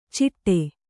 ♪ ciṭṭe